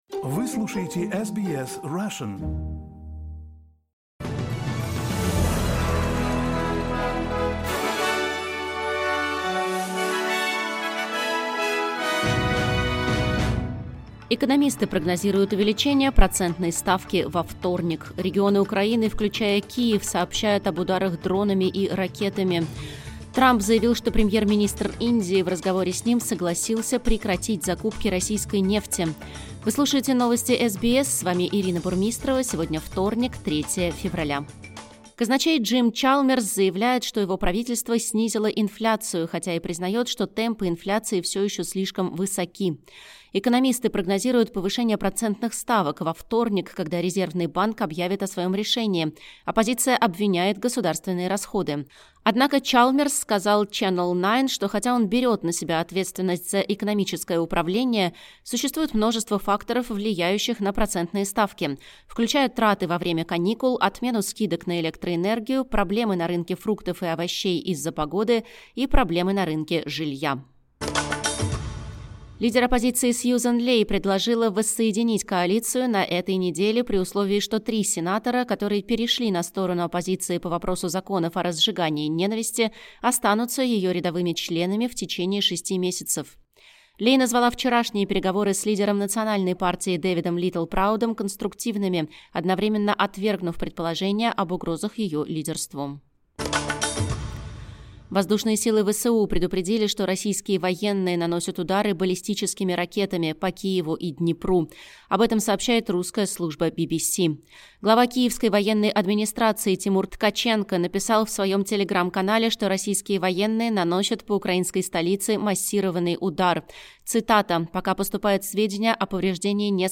Новости SBS на русском языке — 3.02.2026